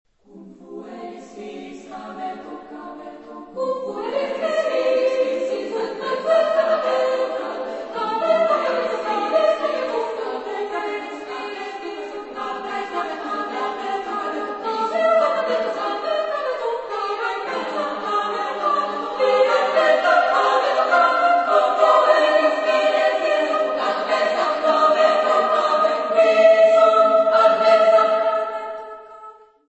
Genre-Style-Forme : Suite ; contemporain ; Profane
Tonalité : polytonal